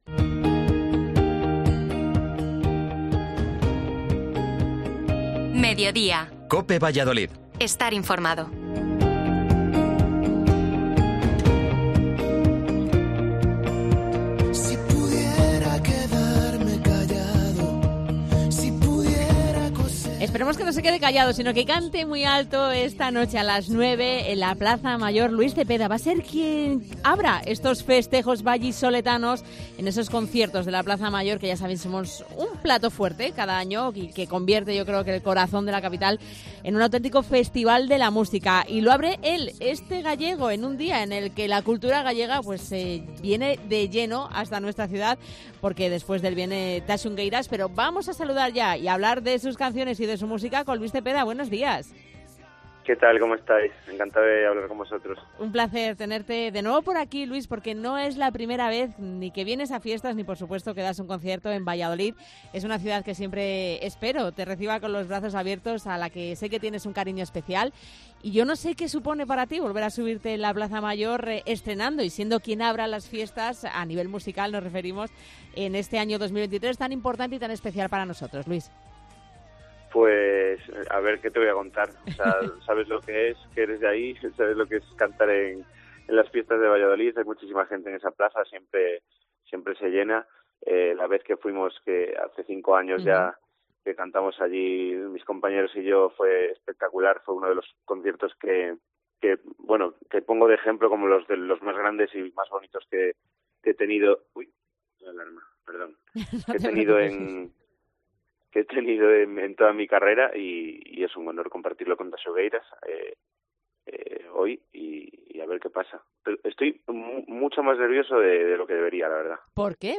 Entrevista a Luis Cepeda que estrena los conciertos de ferias esta noche en la Plaza Mayor